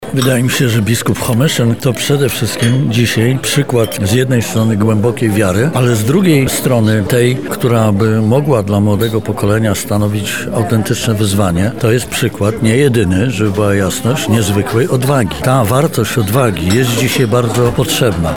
Jan Żaryn– dodaje prof. dr hab. Jan Żaryn